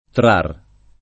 trar [ trar ] tronc. di trarre